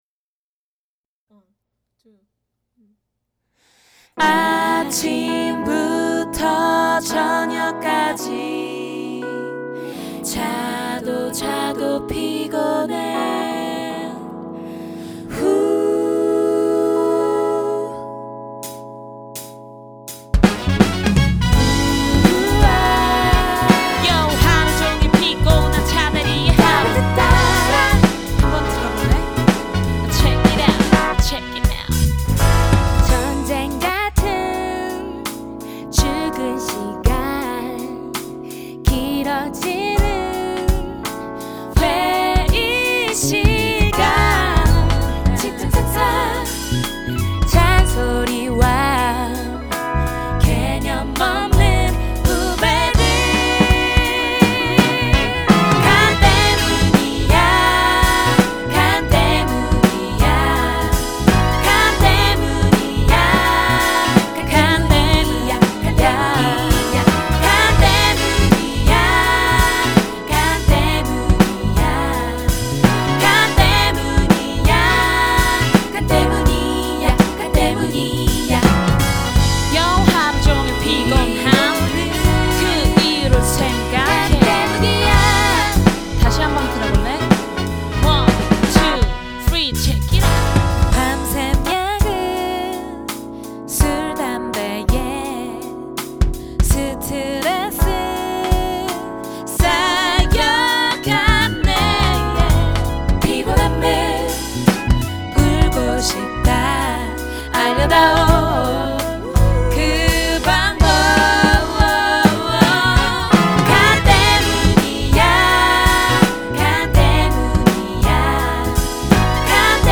7인조 여성밴드